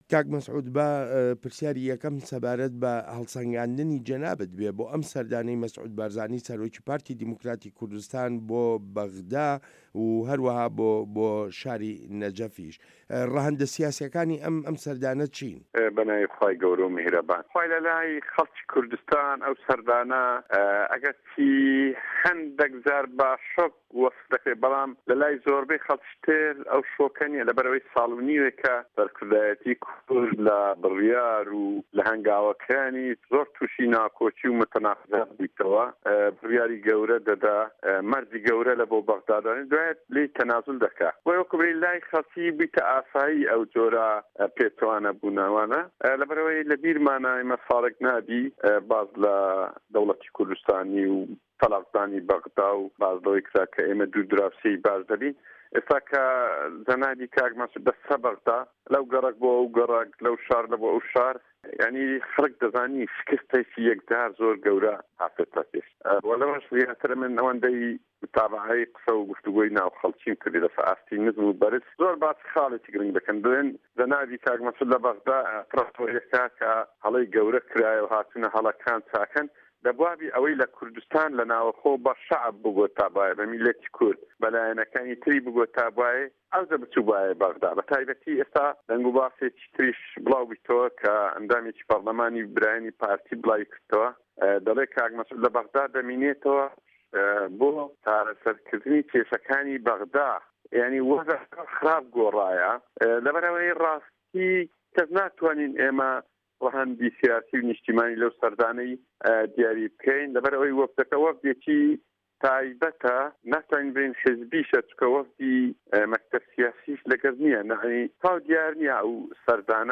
وتووێژ